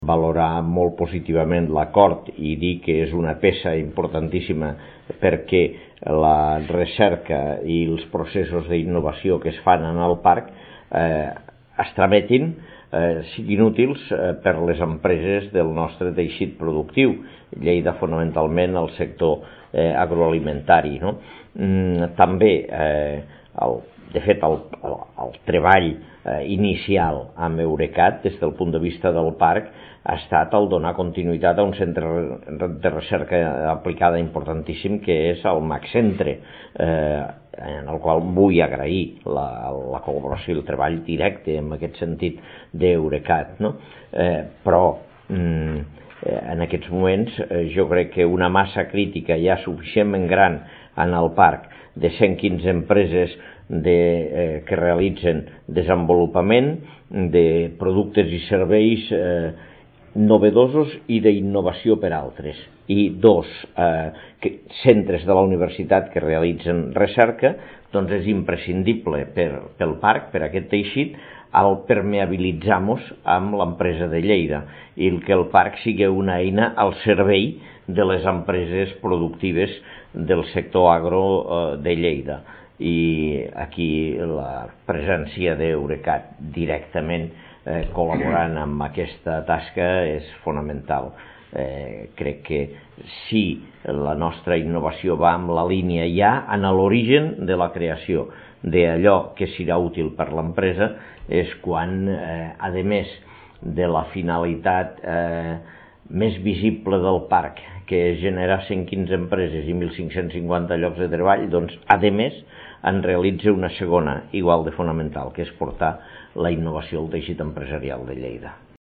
tall-de-veu-de-lalcalde-de-lleida-angel-ros-sobre-el-conveni-amb-eurecat